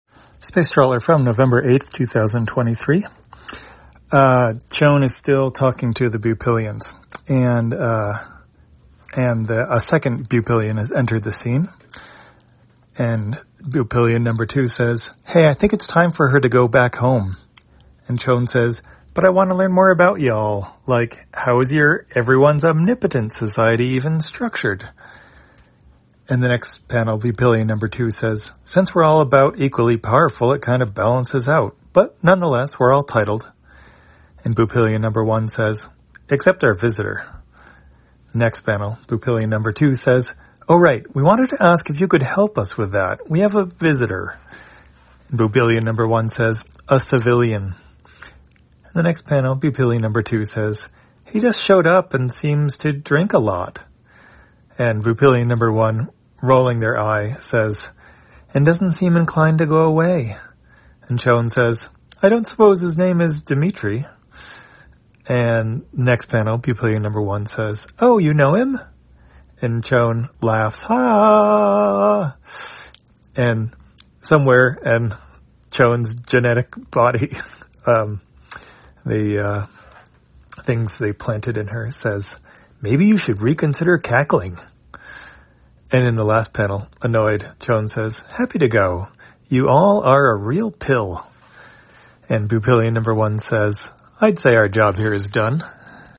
Spacetrawler, audio version For the blind or visually impaired, November 08, 2023.